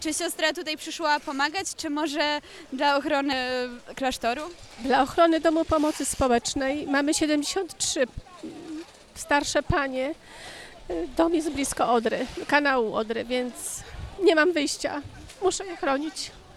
Wśród osób, które przyszły odebrać worki z piaskiem spotkaliśmy siostrę zakonną. Nie powiedziała za wiele, ponieważ łzy napłynęły jej do oczu.
siostra-zakonna.mp3